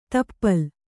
♪ tappal